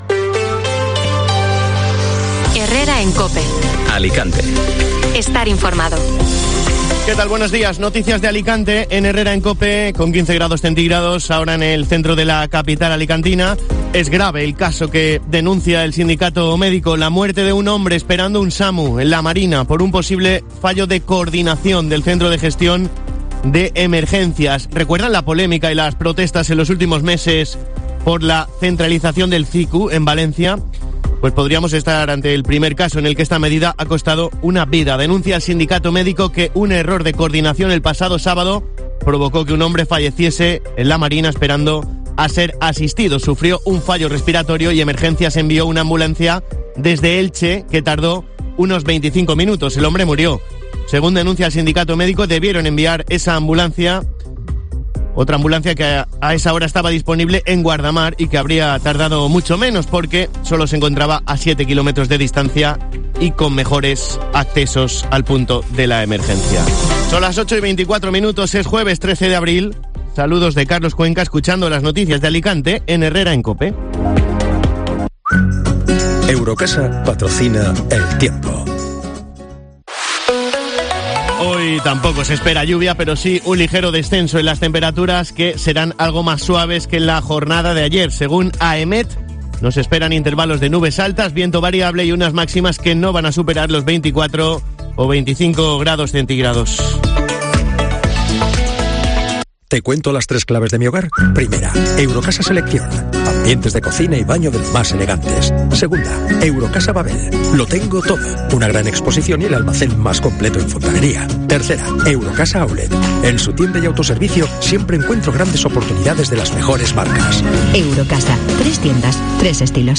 Informativo Matinal Alicante (Jueves 13 de abril)